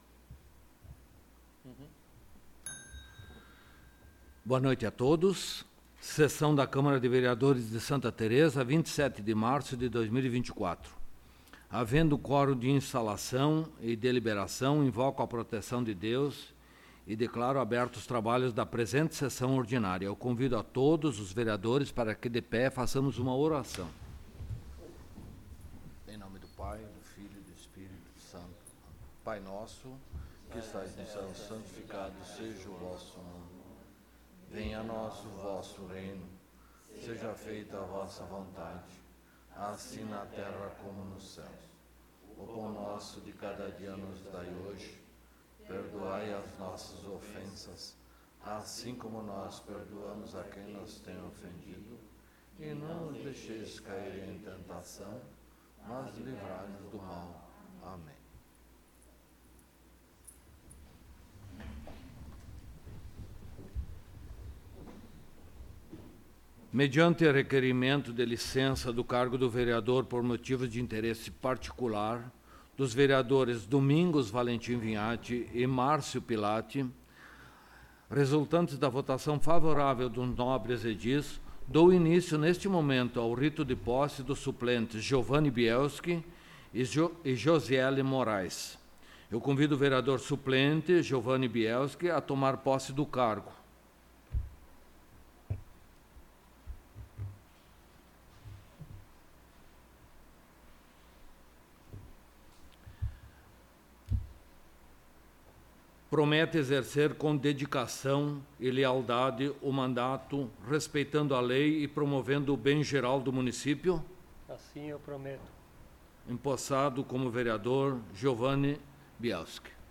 4ª Sessão Ordinária de 2024
Áudio da Sessão